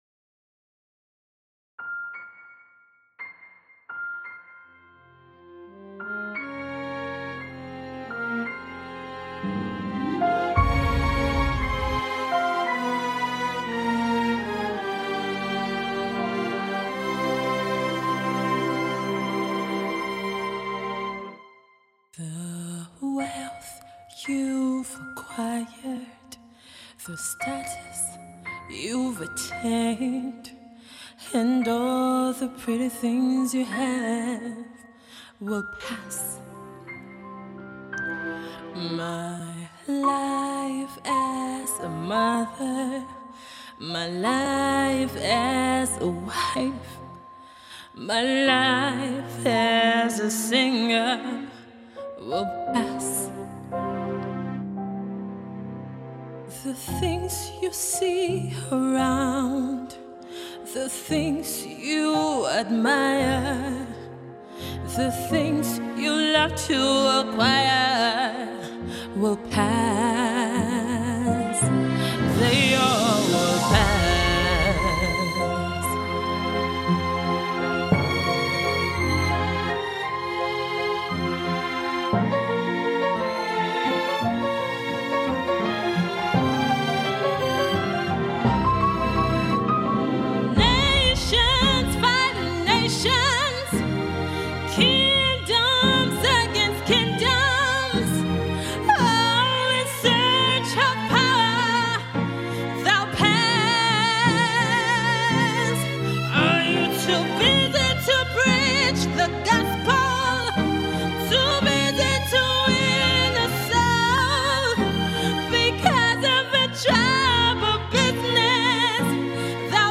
gospel